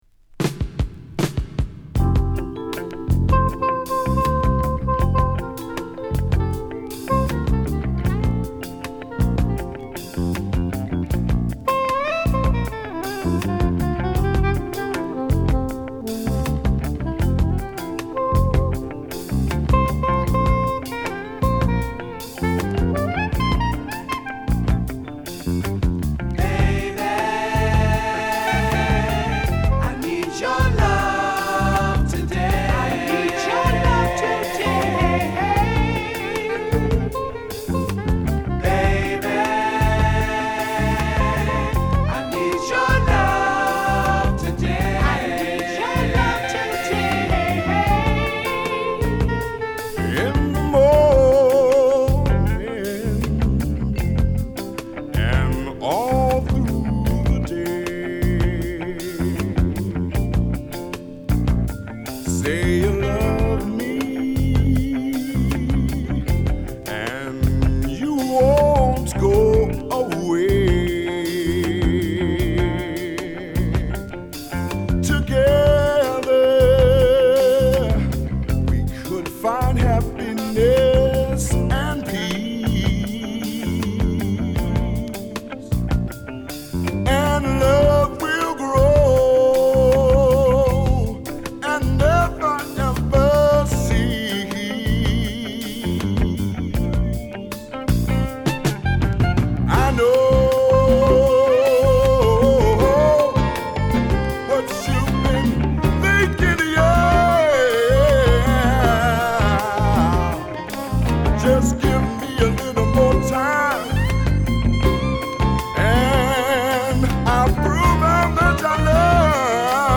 オハイオ出身の4人組セルフ・コンテインド・グループ